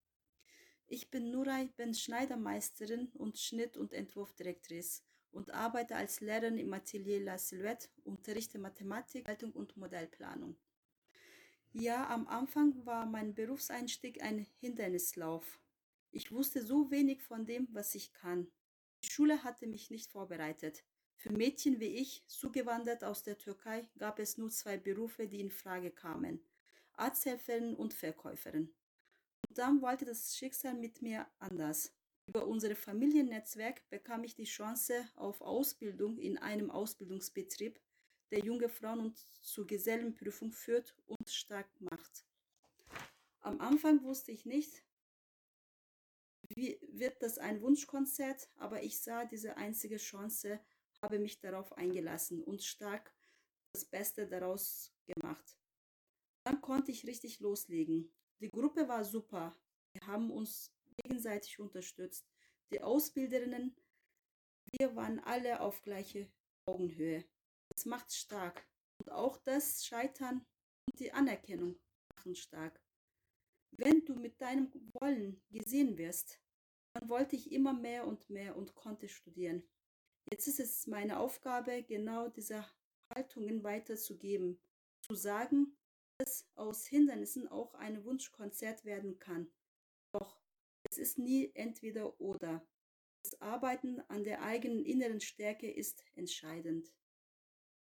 Junge Frauen aus München, mit und ohne Zuwanderungsgeschichte, beschrieben in sehr persönlichen Worten die Bedeutung von Bildung und Berufsausbildung für ihre Biografie.
Produziert wurden die O-Töne speziell für das Fachforum.